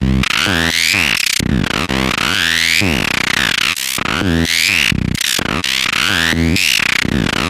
A Moutful Of Guilt Linear Acid Filter Steps 128BPM GMaj
描述：在我的模块化合成器上修补了一个线性振荡器酸性合成器的滤波器修改版。这是一种有趣的声音。
Tag: 128 bpm Electronic Loops Synth Loops 646.19 KB wav Key : G